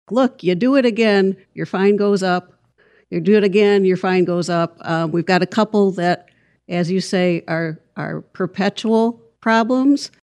City Attorney Catherine Kaufman says their aim is to curb the repeat offenders.